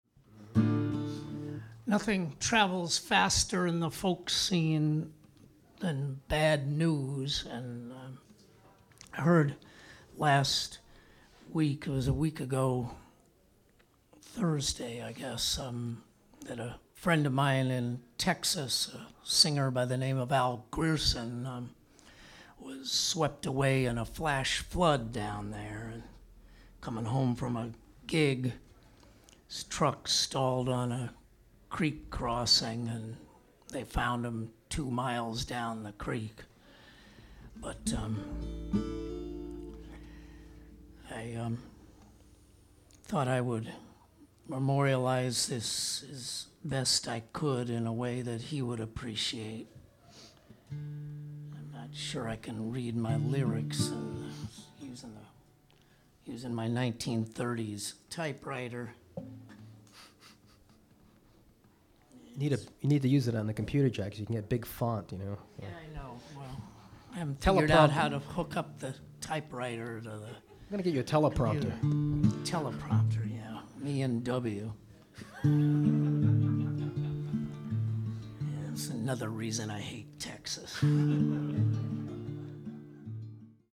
lead acoustic guitar
bass